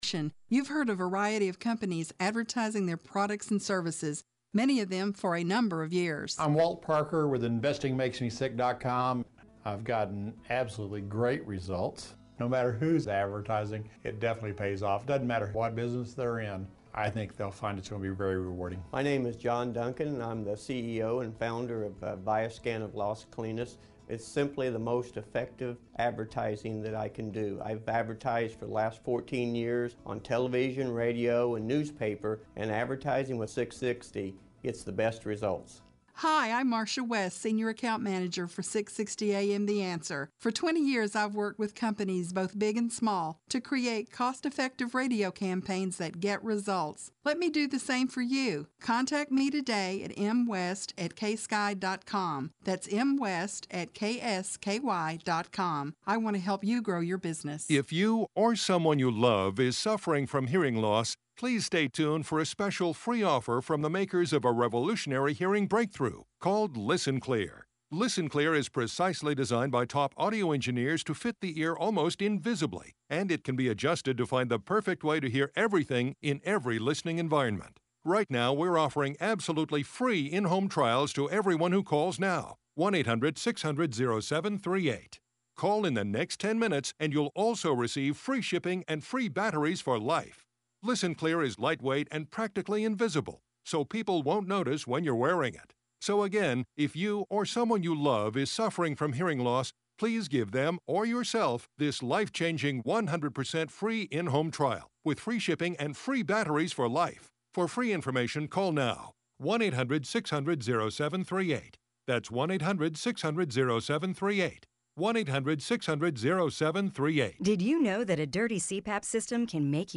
Opening Show with New Name! “America, Can We Talk?”; Must Listen Interview with Allen West on American Liberty